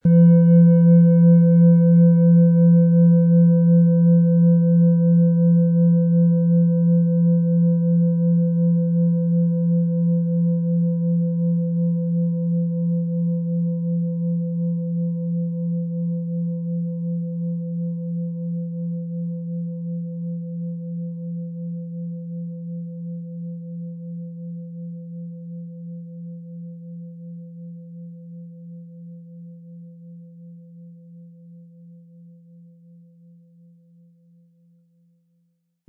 Handgetriebene, tibetanische Planetenklangschale Platonisches Jahr.
• Tiefster Ton: Mond
PlanetentönePlatonisches Jahr & Mond
MaterialBronze